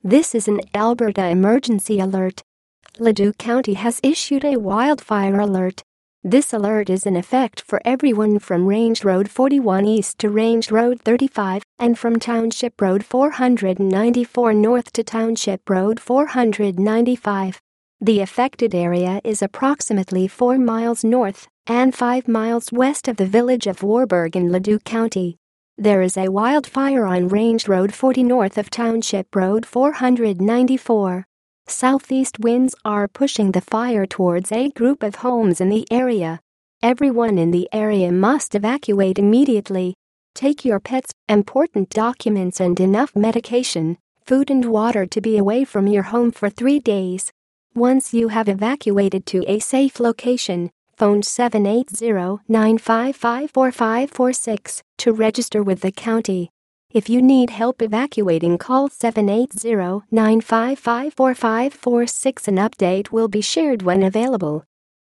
Broadcast Audio